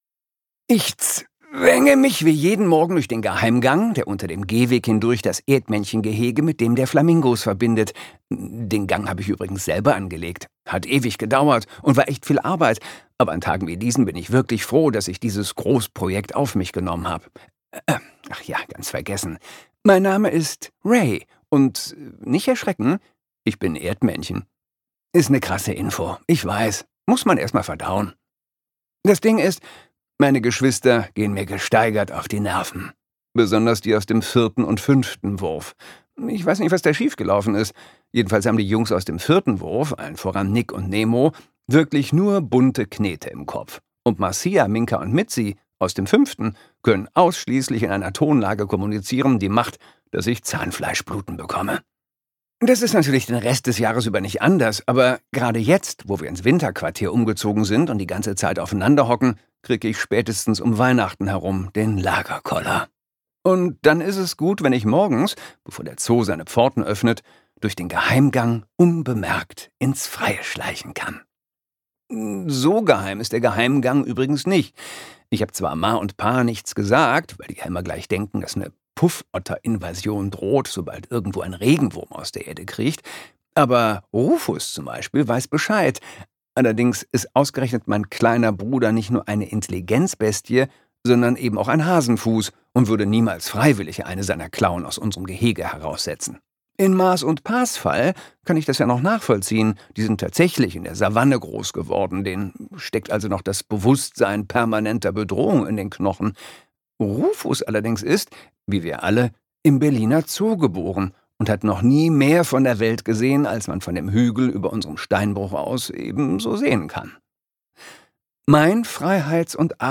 Ausschließlich als Hörbuch erhältlich: das Prequel zur Bestseller-Serie!
Gekürzt Autorisierte, d.h. von Autor:innen und / oder Verlagen freigegebene, bearbeitete Fassung.
Geschenkt Gelesen von: Christoph Maria Herbst